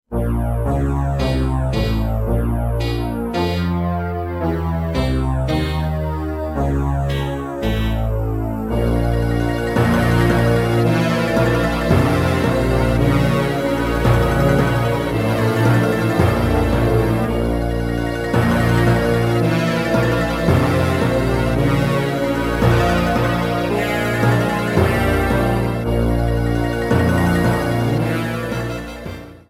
Ripped from the remake's files
trimmed to 29.5 seconds and faded out the last two seconds